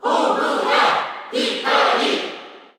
Crowd cheers (SSBU)
Pyra_&_Mythra_Cheer_Korean_SSBU.ogg